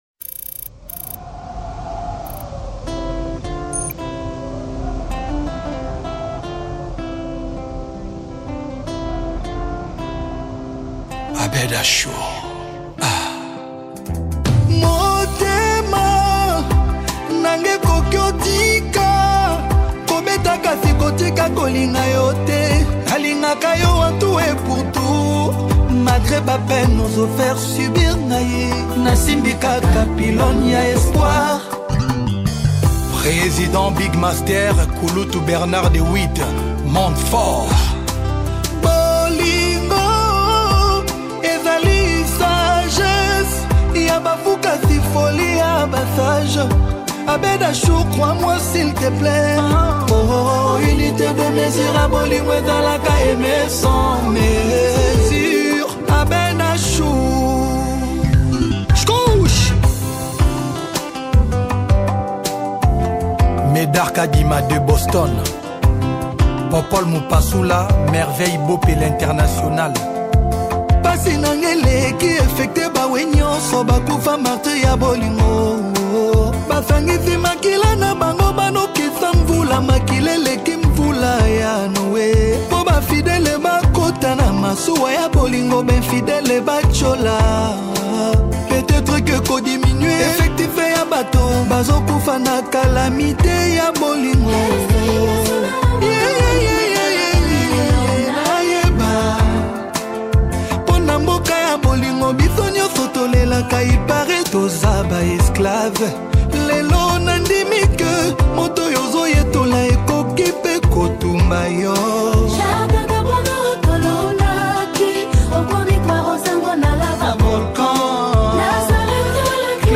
is a vibrant track